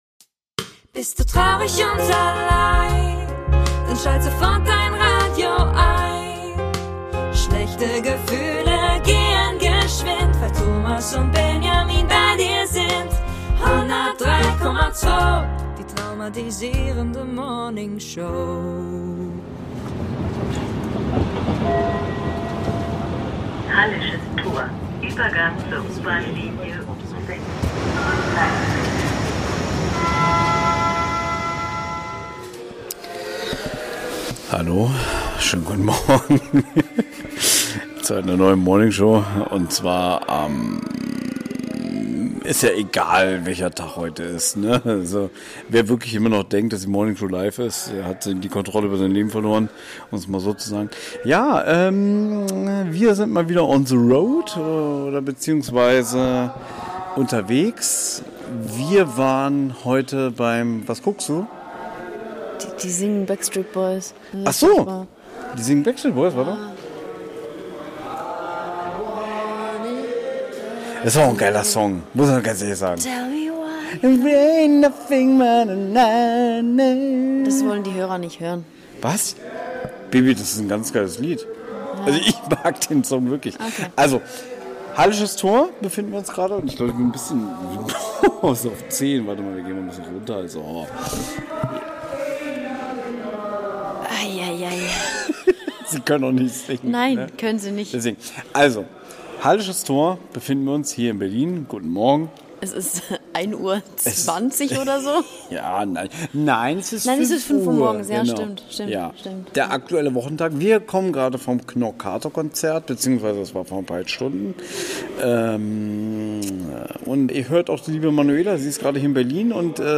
Aber wenigstens die Leute auf dem Bahnsteig
haben schön gesungen :)